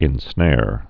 (ĭn-snâr)